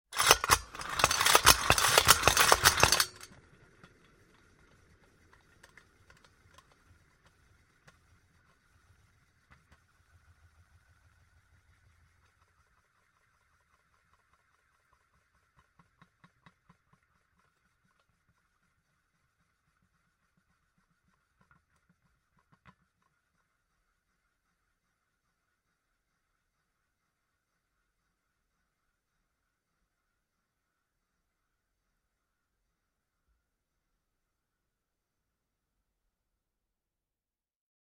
На этой странице собраны звуки юлы — от легкого жужжания до быстрого вращения.
Звук продолжительного вращения юлы